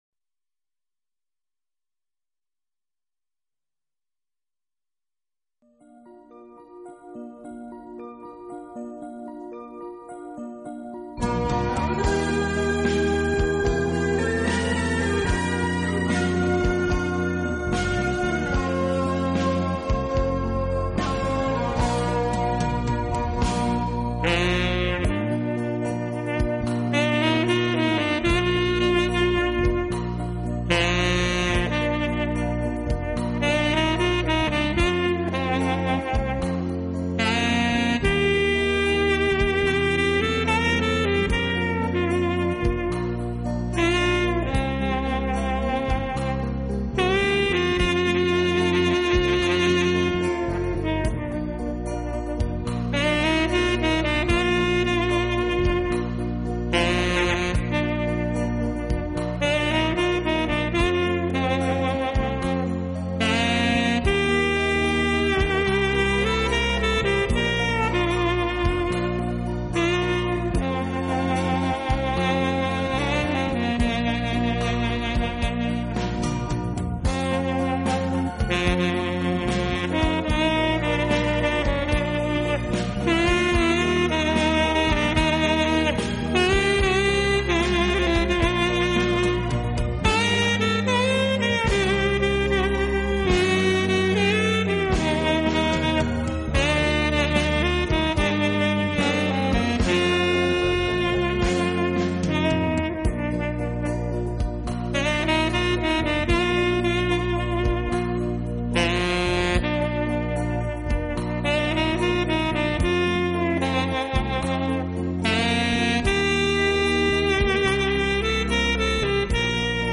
悠悠的萨克斯，吹响的是那淡淡的感伤，浓浓的思绪；吹出的是你的往昔，你的
等待，你的情怀，恰如心在吟唱，沏上一杯浓香的咖啡，耳边飘起怀旧与感性的